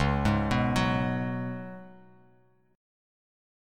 C#7sus4 chord